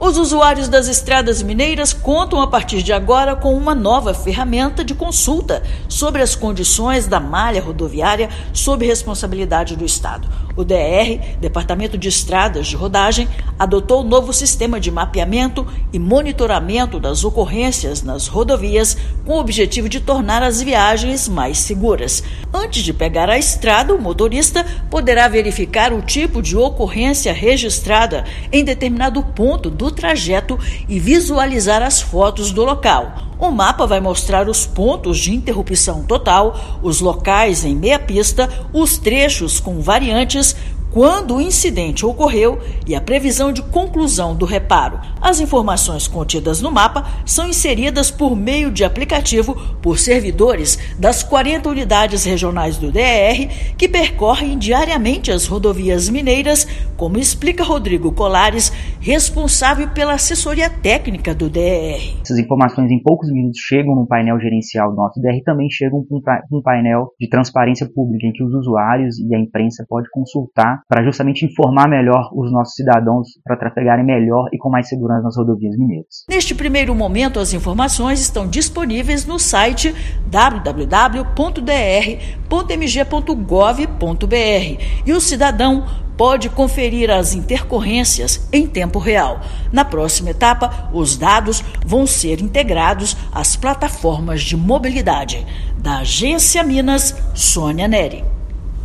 Antes de iniciar a viagem, motorista vai poder verificar possíveis ocorrências no trajeto que vai percorrer, além de fotos do local. Ouça matéria de rádio.